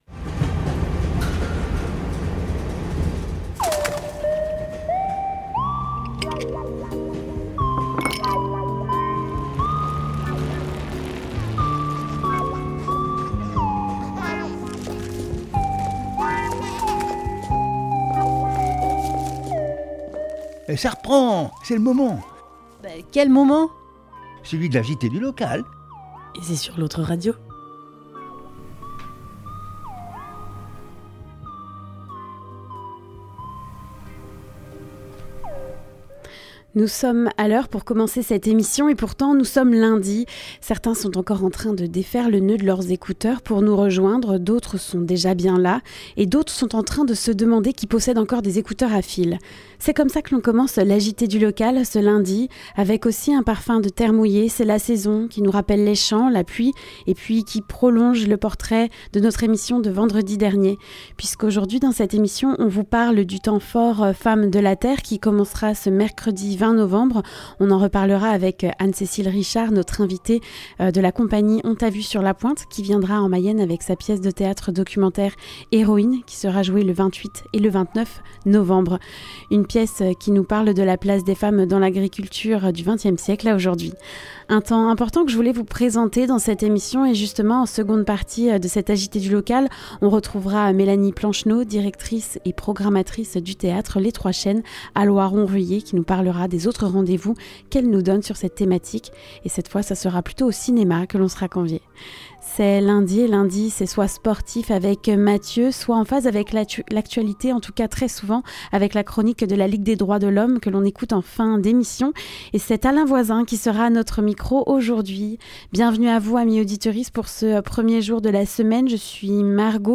La revue de presse du Haut Anjou